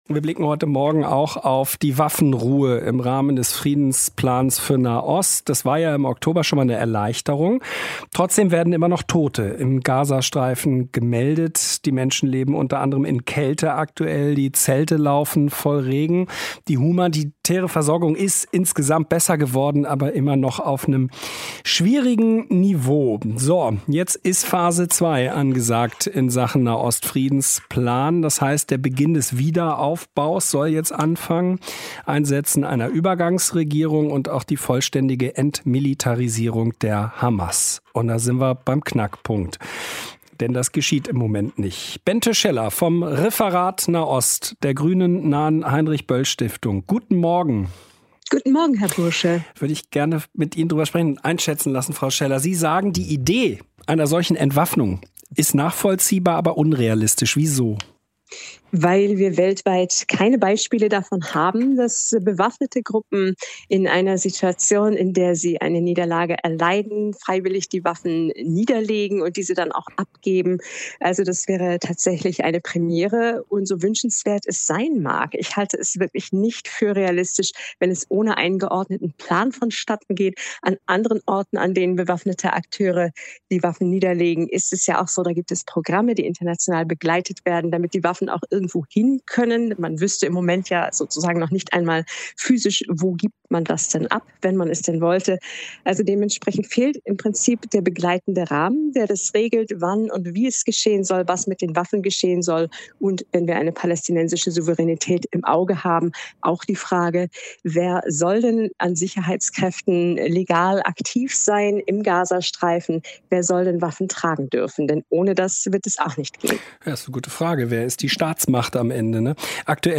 Der Friedensplan für den Gaza-Streifen tritt auf der Stelle. Ein WDR5-Interview erhellt die Gründe hier.